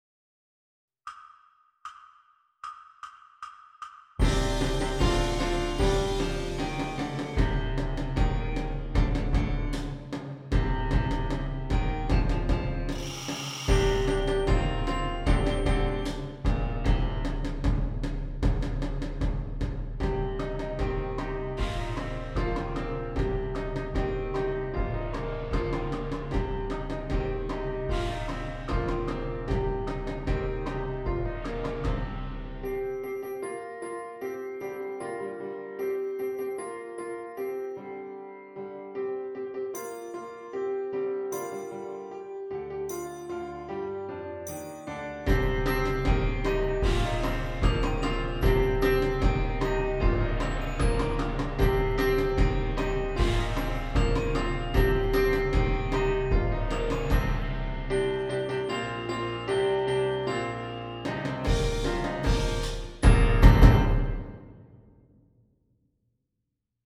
Flex Band Level